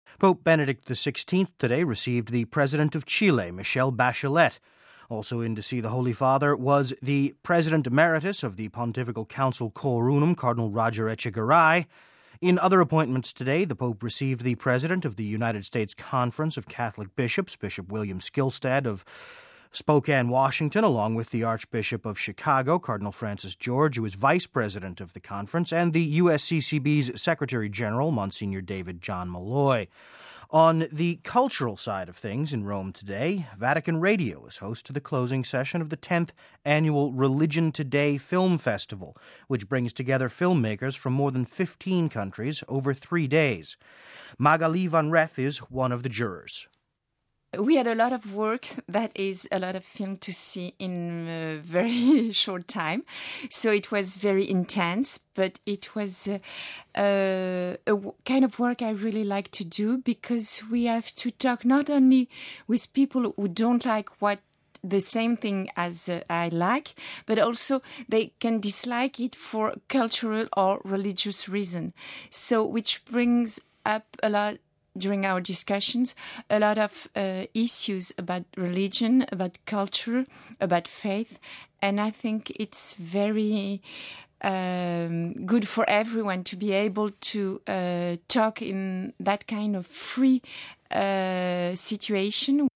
reports on the Pope's activities today...